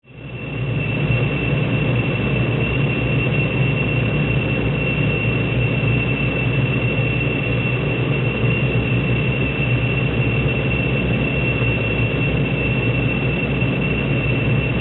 Free SFX sound effect: Rough Turbine.
Rough Turbine
Rough Turbine.mp3